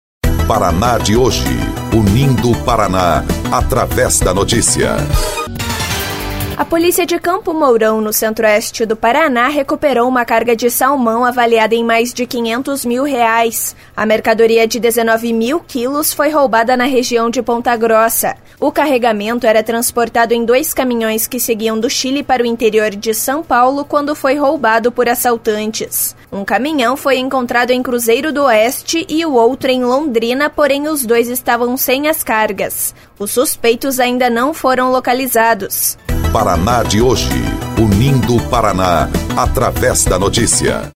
BOLETIM - Polícia recupera carga de salmão avaliada em mais de R$500 mil